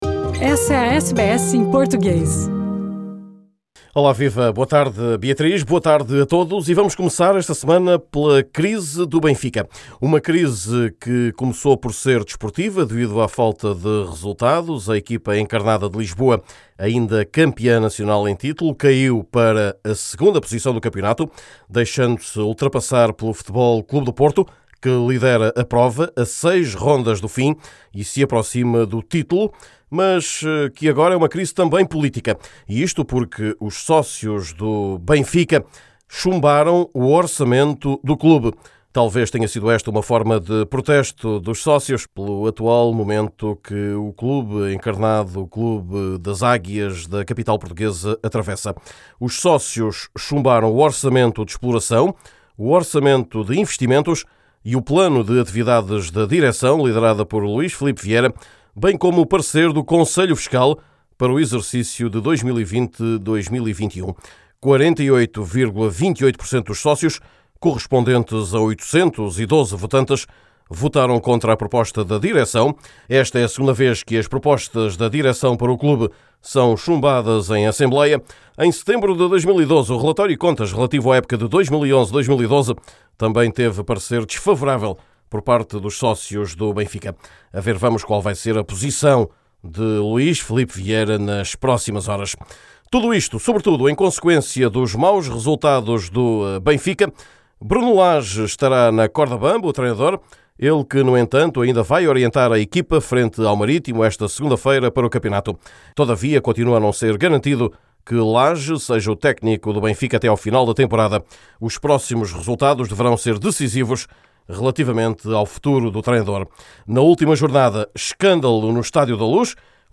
num boletim em que falamos ainda da lesão que acabou com a carreira de Mathieu